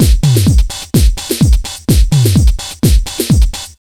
127BEAT8 4-L.wav